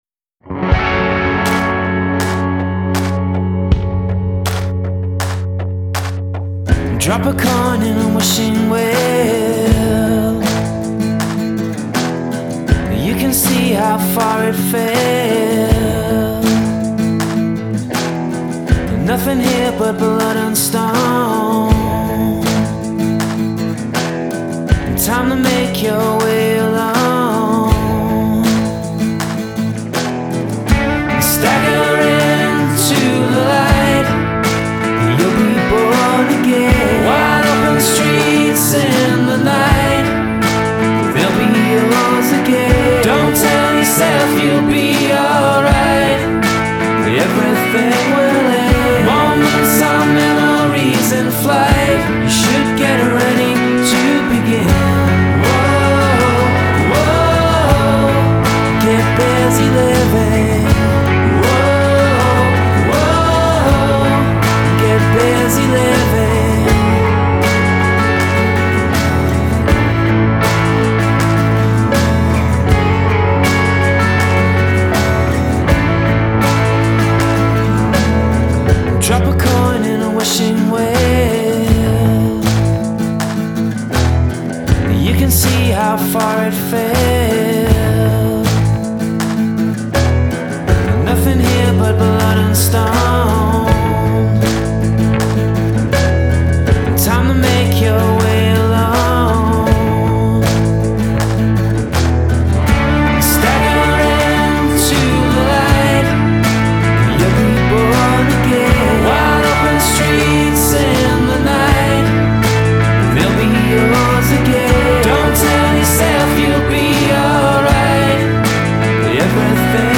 A sort of poppy with some rock hints
It’s uplifting too